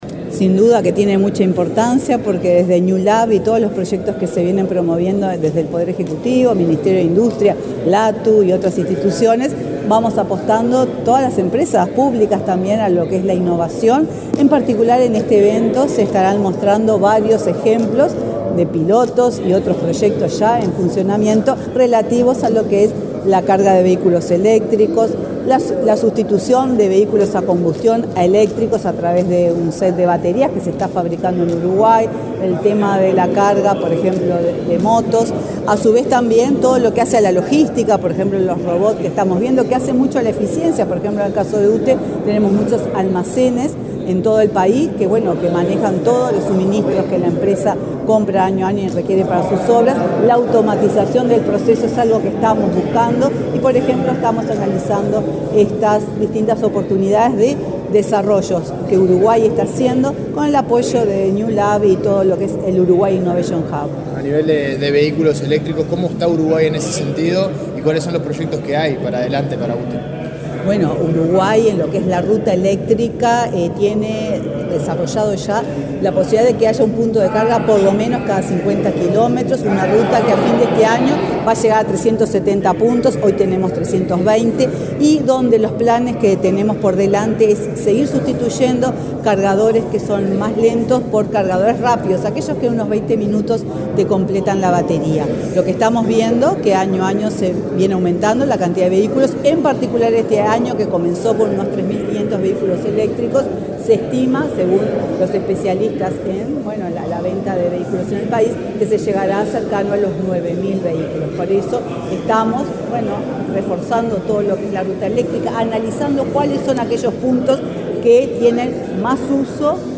Declaraciones de la presidenta de UTE, Silvia Emaldi, a la prensa
Declaraciones de la presidenta de UTE, Silvia Emaldi, a la prensa 28/08/2024 Compartir Facebook X Copiar enlace WhatsApp LinkedIn Este miércoles 28 en el Laboratorio Tecnológico del Uruguay, la presidenta de la UTE, Silvia Emaldi, dialogó con la prensa, durante su participación en la muestra Newlab Uruguay, la plataforma colaborativa para acelerar la innovación de las empresas locales y atraer a extranjeras.